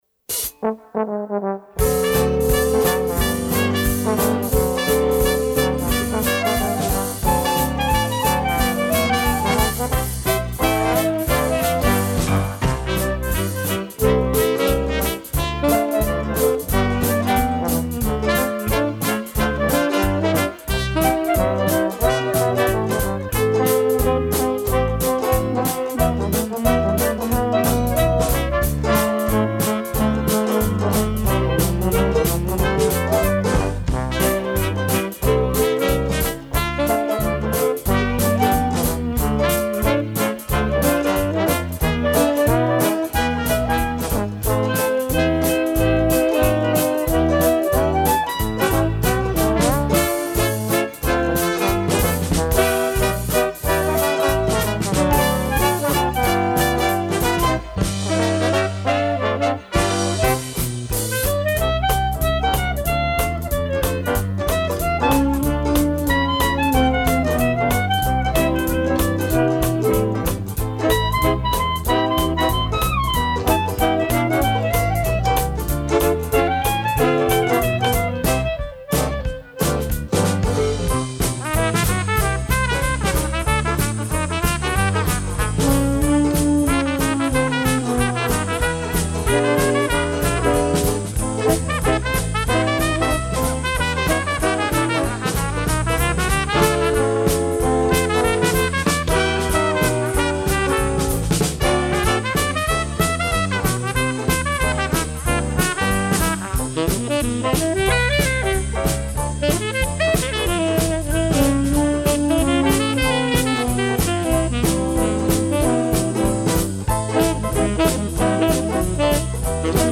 Gattung: Dixieland Combo
Besetzung: Dixieland Combo